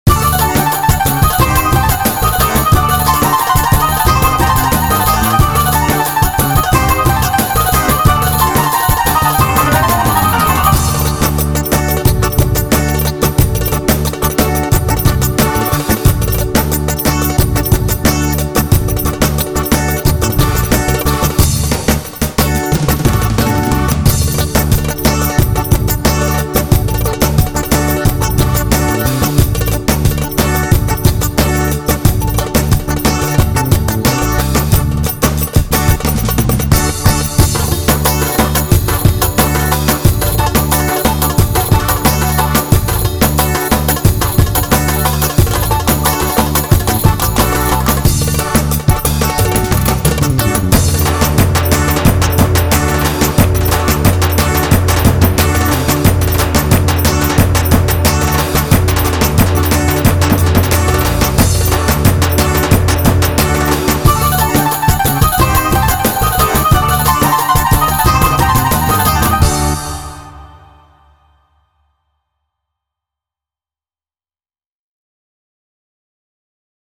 Yeni Yazdığım Çiftetelli ritminin demosunu sizlerin beğenisine sunuyorum.4 İntro-4 Ending 4 variation ve 3 adet (Atak)Fill'den oluşmaktadır.dinliyeceğiniz bölümler İntro4-3 Fill(Atak) -4 Variation ve Ending 4